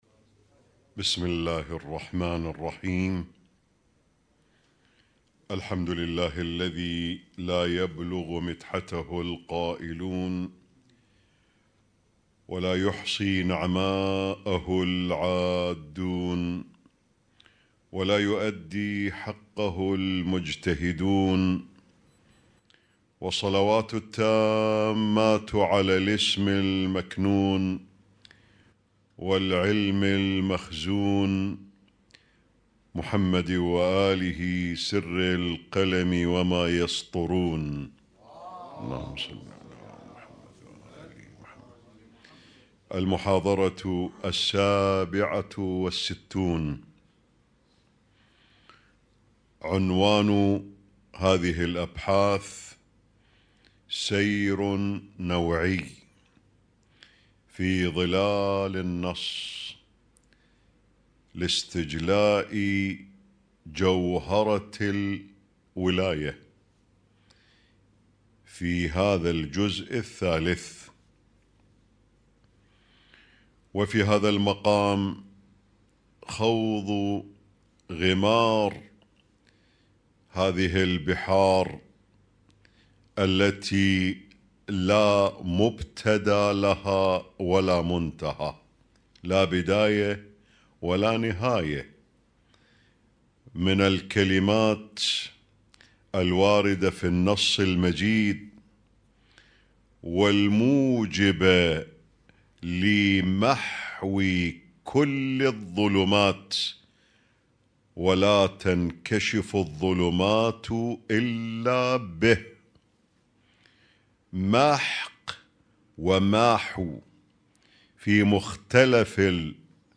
اسم التصنيف: المـكتبة الصــوتيه >> الدروس الصوتية >> الرؤية المعرفية الهادفة